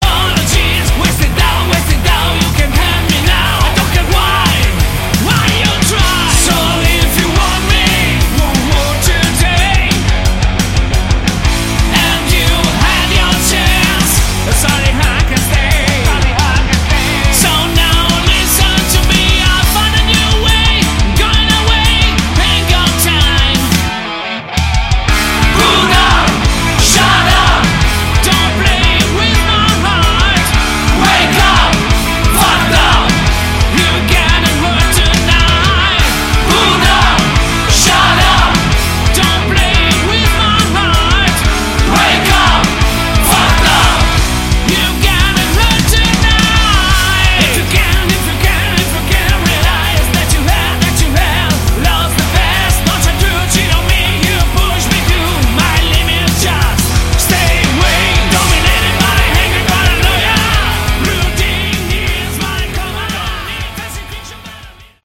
Category: Sleaze Glam
Vocals
Bass, Backing Vocals
Guitar
Drums, Backing Vocals